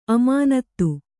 ♪ amānattu